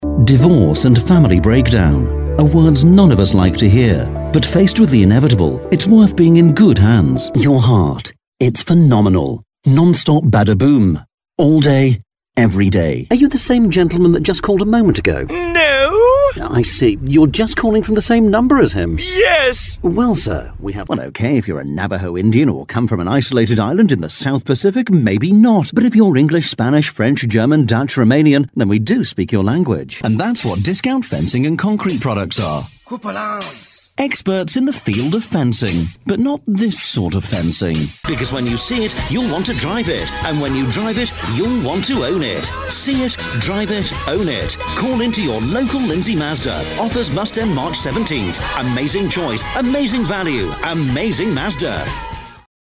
Friendly, natural yet corporate sounding neutral British English voice.
Sprechprobe: Werbung (Muttersprache):
Friendly, natural corporate neutral UK English.